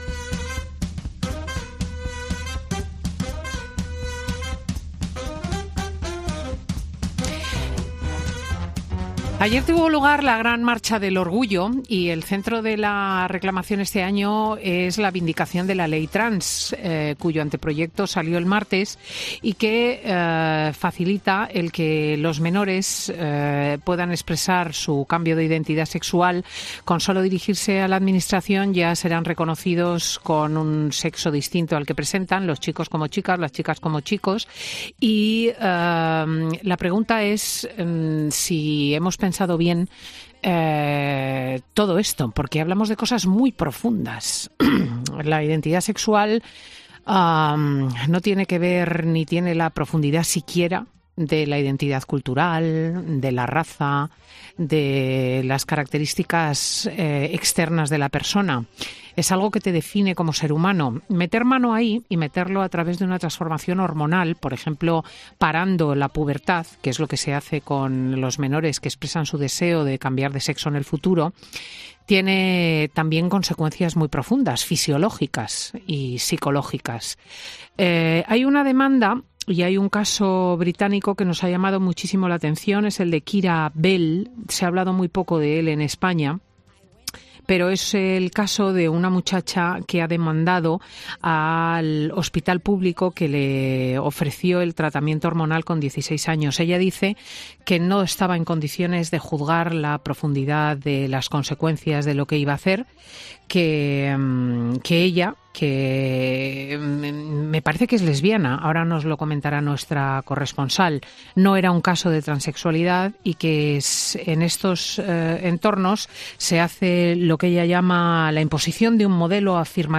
El juez de menores Emilio Calatayud opina en Fin de Semana con Cristina sobre la noticia de una joven británica que ha ganado un batalla legal por...